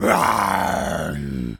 tas_devil_cartoon_08.wav